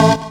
54_12_organ-A.wav